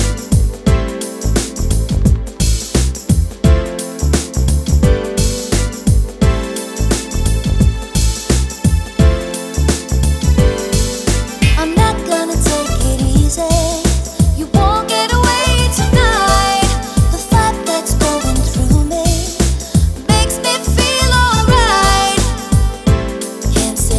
Radio Edit for Solo Male Pop (1990s) 3:44 Buy £1.50